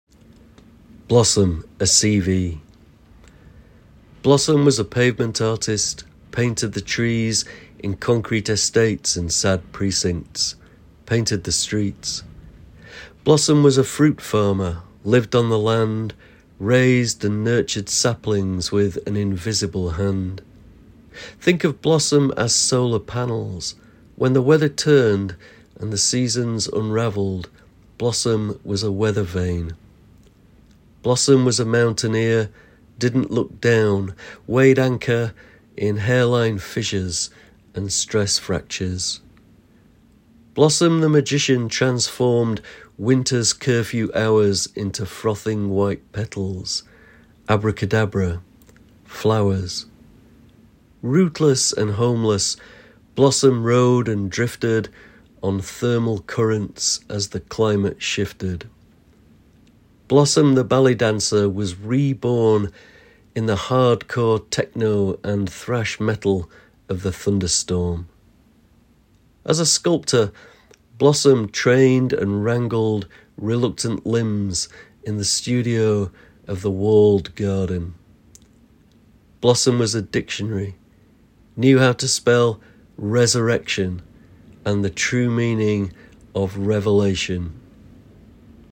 Poet Laureate Simon Armitage reads his poem Blossom a CV. Blossom a CV is part of Blossomise, a collection of poetry and music launched in 2024 to celebrate the arrival of spring.